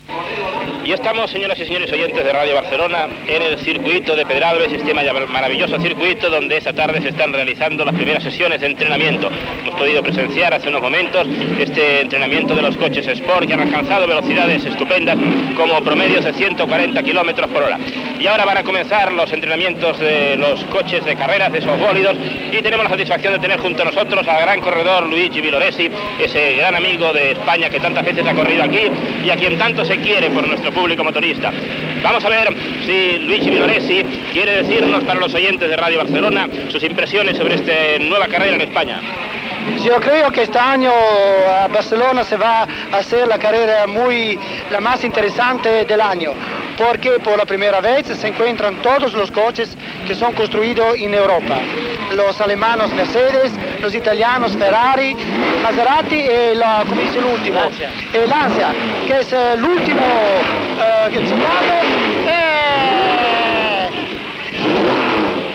Entrevista al pilot de cotxes Luigi Villoresi en els entrenaments del XII Gran Premio Penya Rhin celebrat al circuit de Pedralbes de Barcelona.
Esportiu